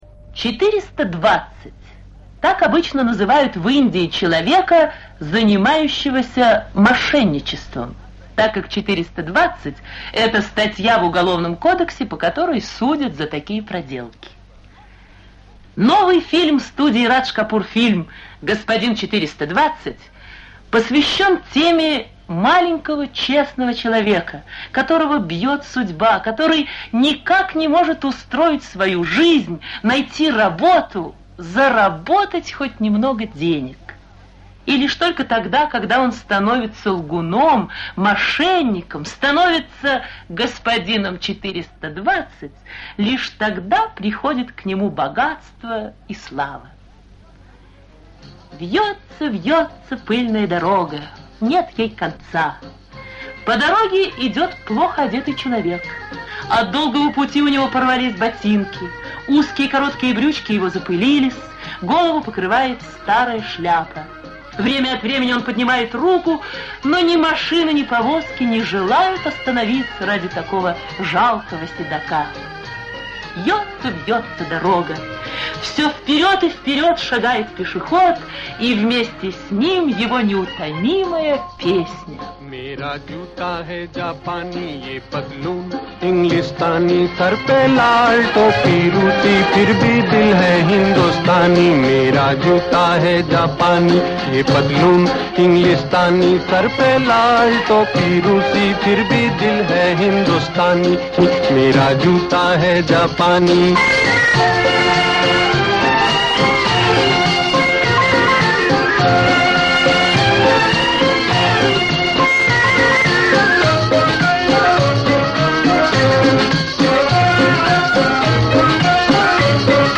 Радиорассказ по фильму "Господин 420". Записан в конце 50-х с приёмника на бытовую ленту.